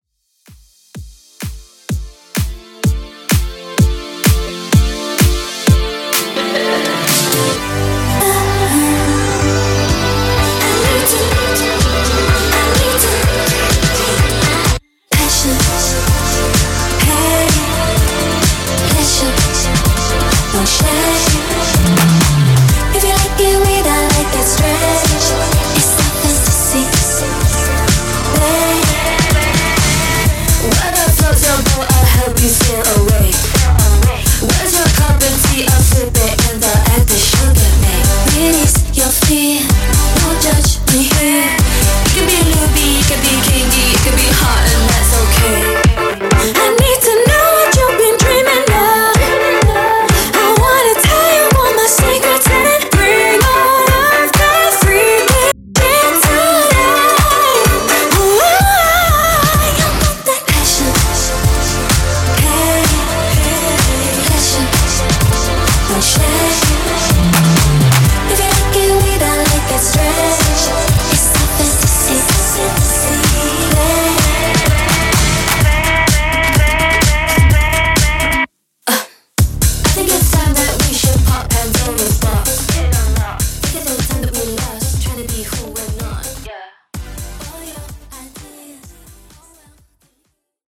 Genre: 90's
Clean BPM: 120 Time